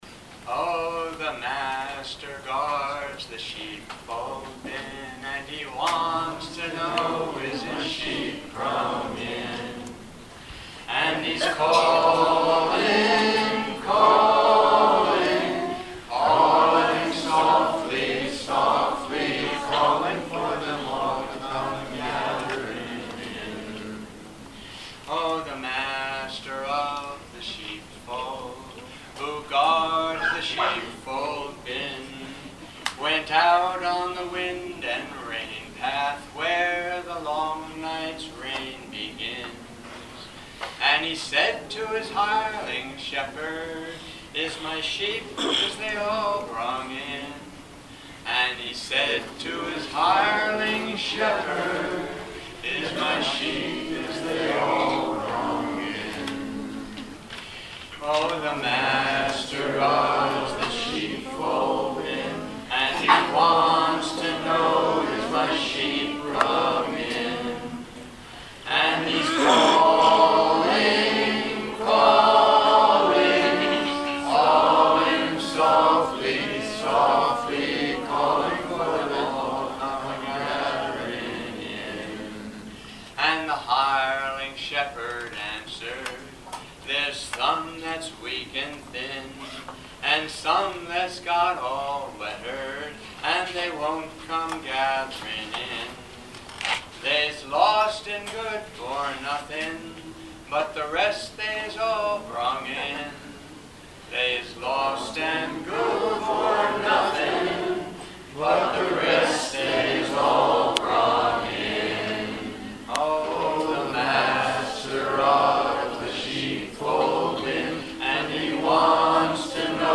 Though more than half the songes were recorded at the campers concert held at the end of the week, some were recorded at informal late night singing sessions.